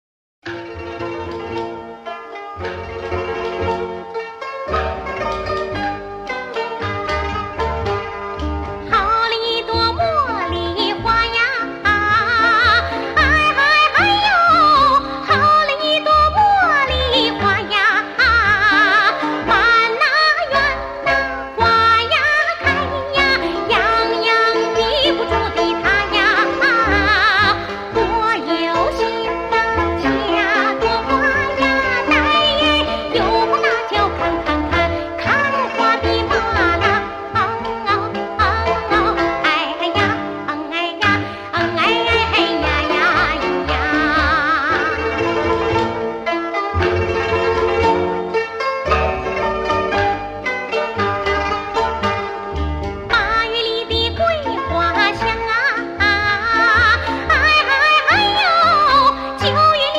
独唱：茉莉花（东北）